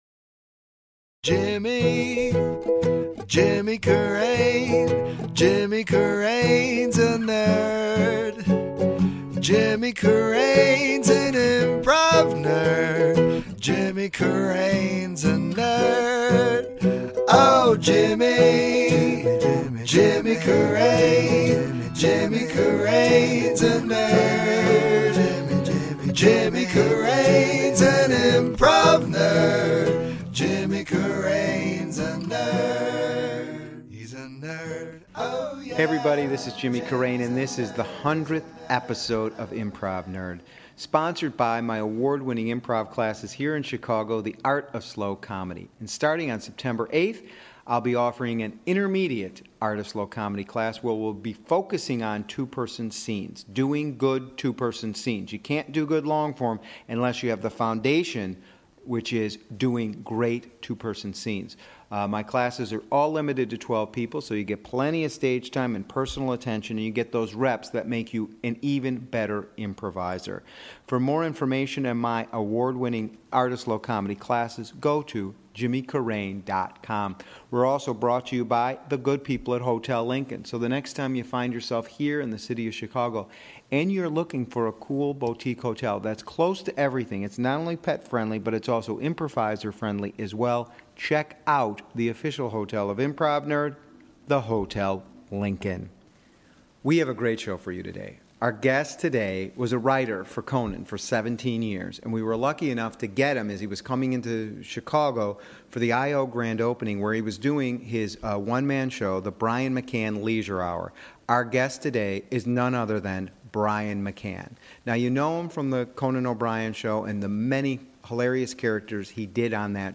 interviews
It's an episode filled with lots of laughs, great stories, and thoughtful advice for improvisers.